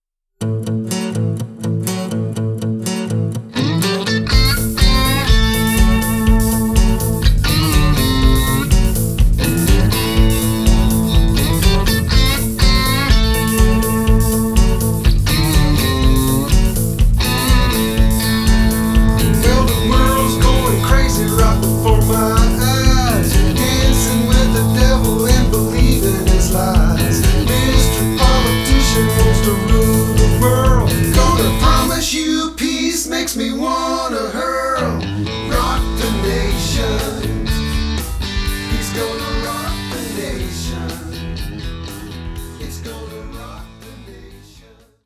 Website background music